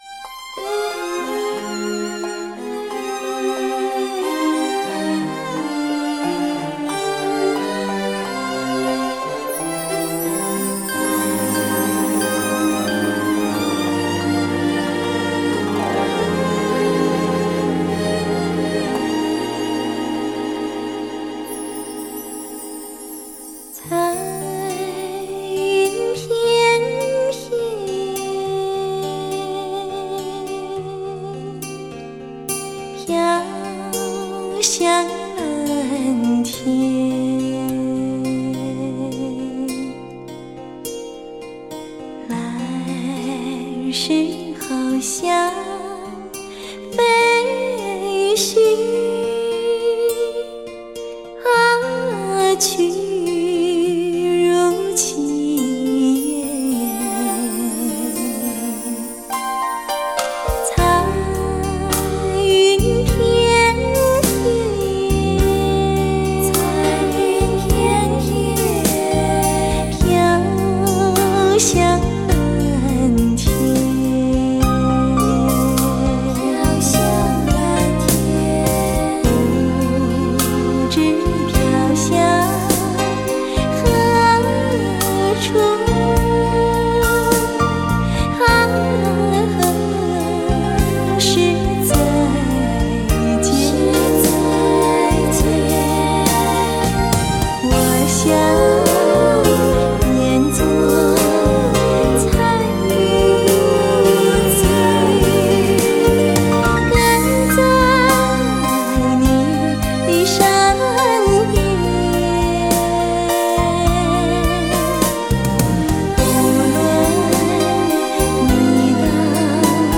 超级柔情 经典贴心
歌声甜美，谢谢分享